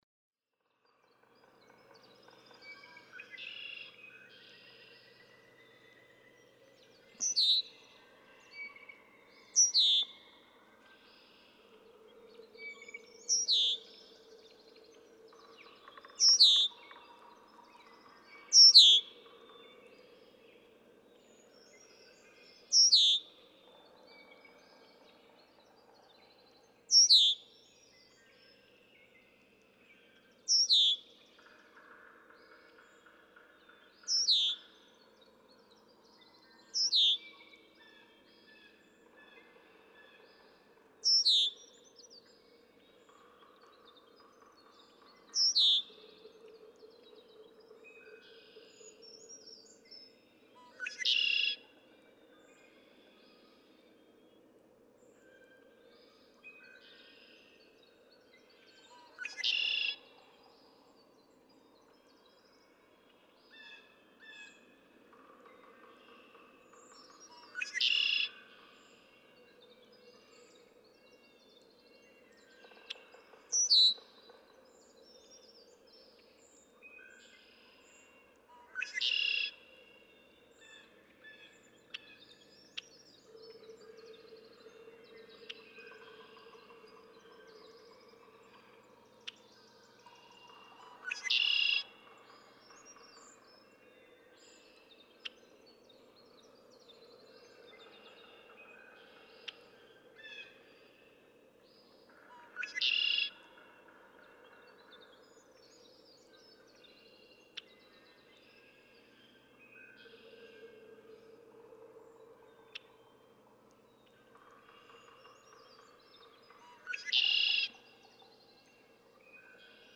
♫496: Another 2018 dawn from the same territory. Sometimes he is near the microphone, other times more distant, making it a little difficult to follow him. 6:25-7:32 a.m. Sunrise at 6:37 a.m.. April 24, 2018. Atlanta, Michigan (1:07:00)
496_Red-winged_Blackbird.mp3